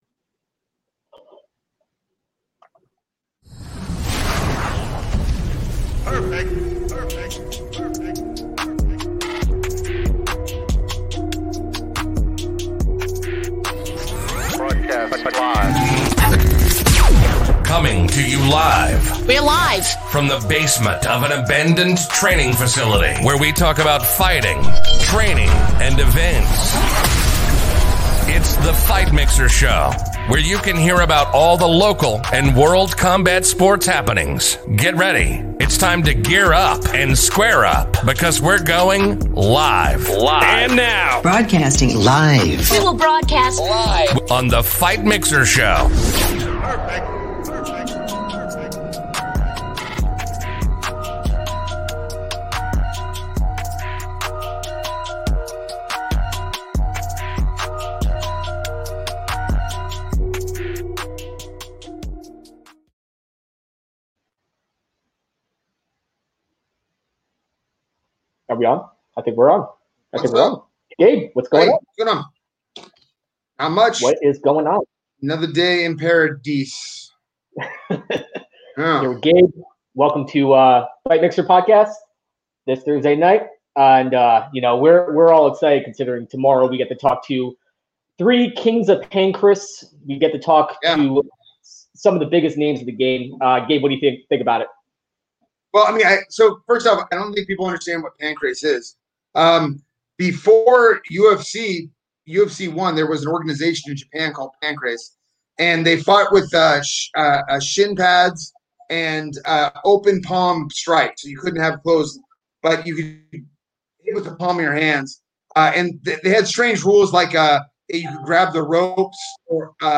Interview with UFC Fighter "Smile'n" Sam Alvey - Fight Mixer